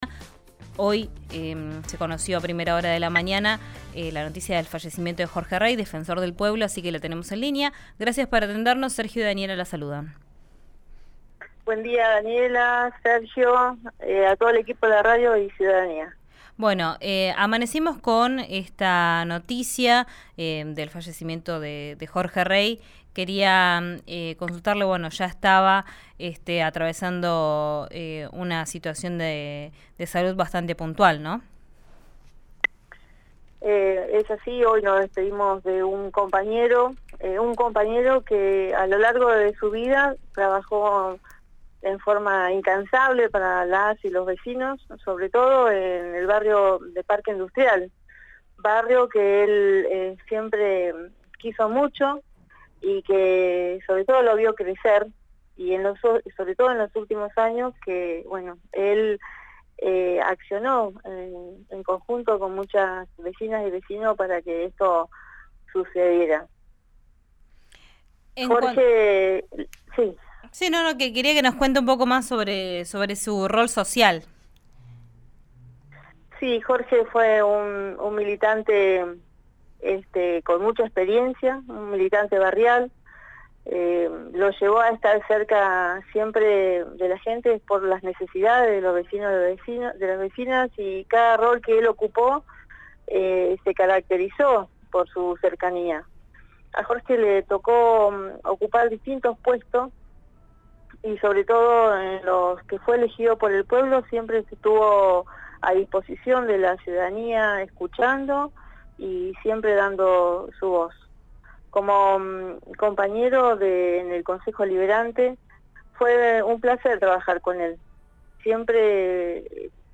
Escuchá a Claudia Argumero, presidenta del Concejo Deliberante de Neuquén, en RÍO NEGRO RADIO: